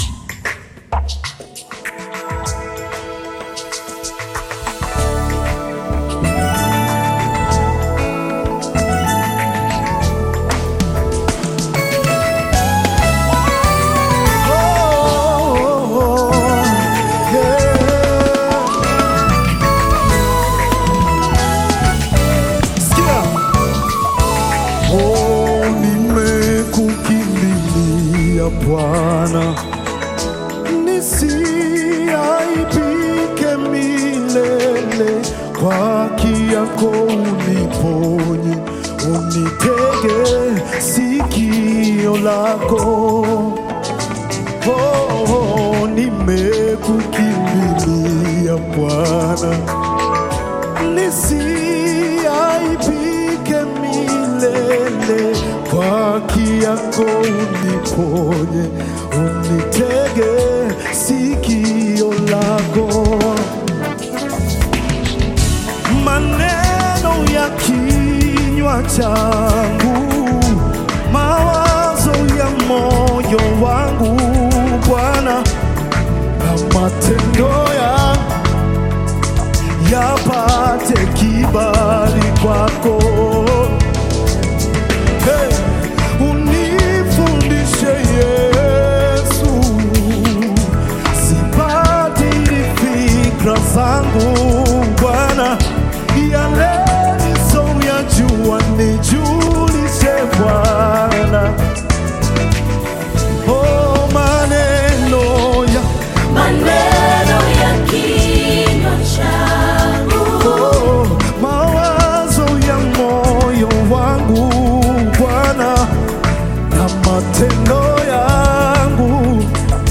Praise music
Praise Gospel music track